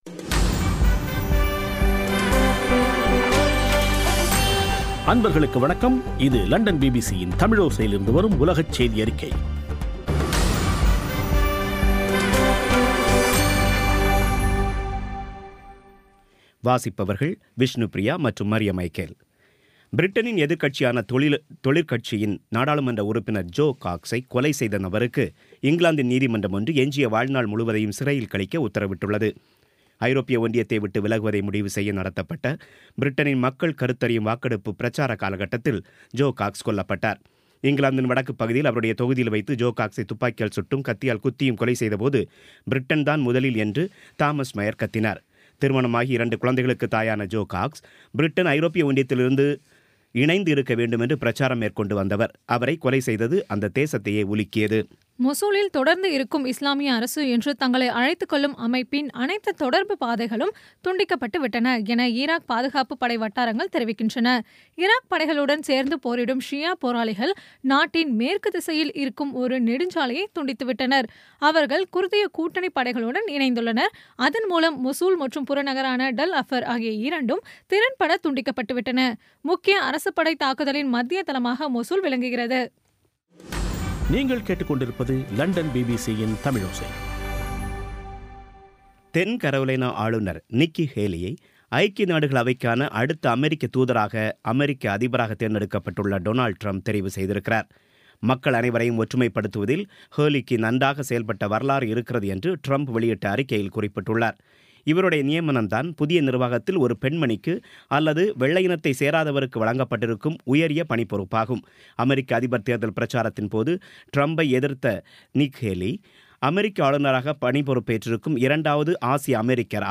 பி பி சி தமிழோசை செய்தியறிக்கை